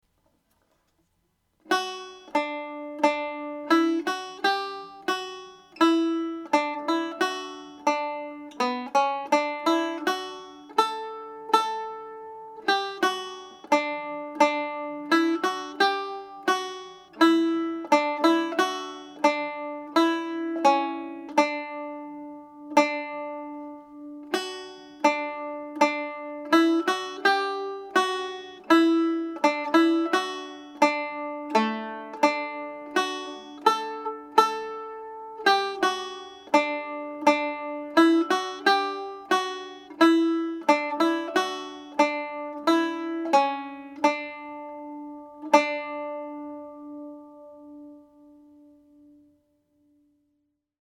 second part played slowly
Kerry-Polka_2nd-part.mp3